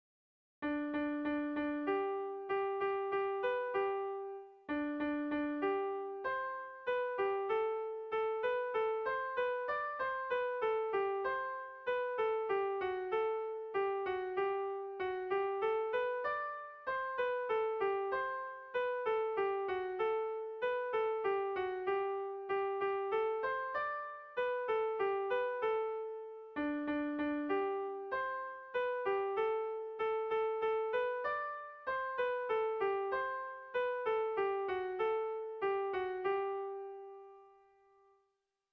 Erlijiozkoa
Hamarreko handia (hg) / Bost puntuko handia (ip)
ABDA2D